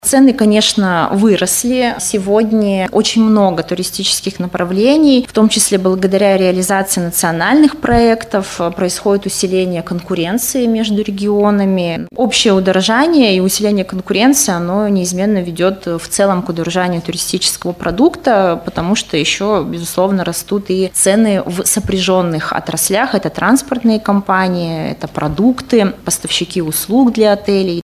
на пресс-конференции «ТАСС-Урал».